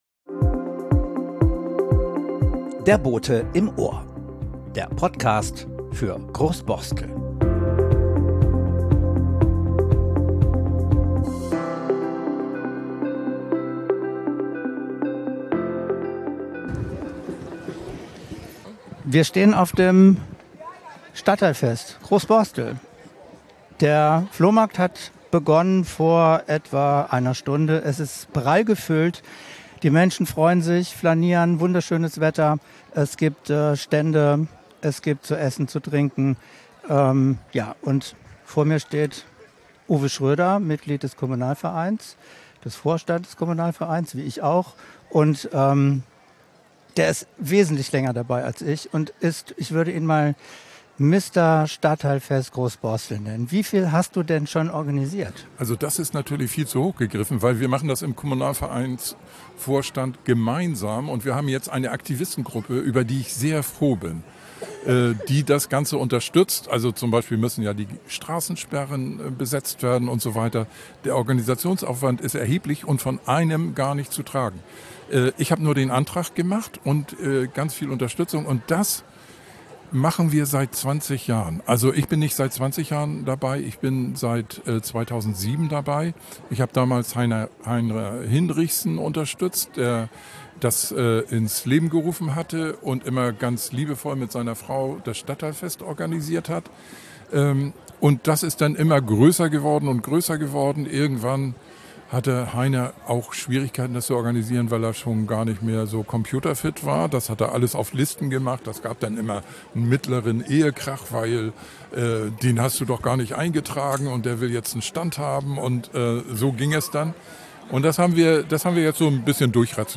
#42 Das Weltdorf: Ein unterhaltsamer Rundgang über das Stadtteilfest 2024
Das Stadtteilfest 2024 für alle zum Nachschlendern: Mit Vereinen aus Groß Borstel, mit Statements aus der Politik, mit einem Wunderland-Macher, Bürgerinnen am Flohmarktstand und - mit einem Flashmop als Protestaktion gegen die Schließung des Cafés im Stavenhagenhauses.